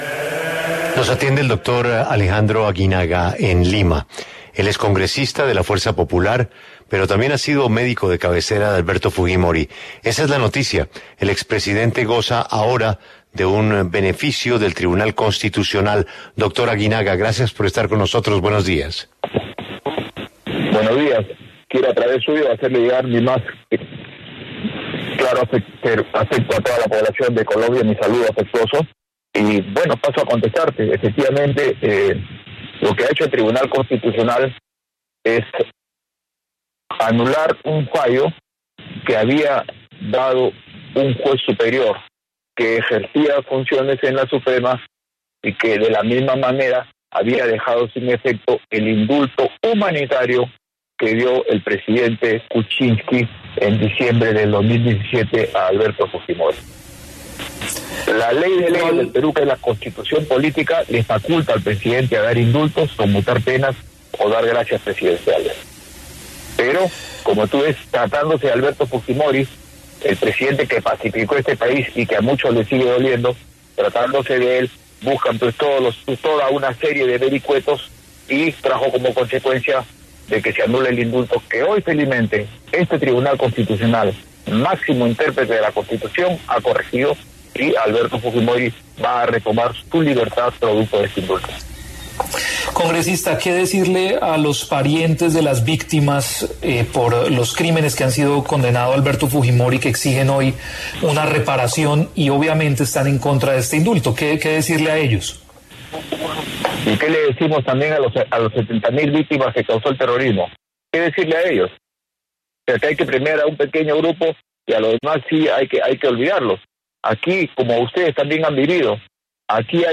Alejandro Aguinaga, congresista de Fuerza Popular, amigo y médico de cabecera de Alberto Fujimori, habló en La W sobre la decisión de aprobar la libertad del expresidente de Perú.
En el encabezado escuche la entrevista completa con Alejandro Aguinaga, congresista de Fuerza Popular, amigo y médico de cabecera de Alberto Fujimori.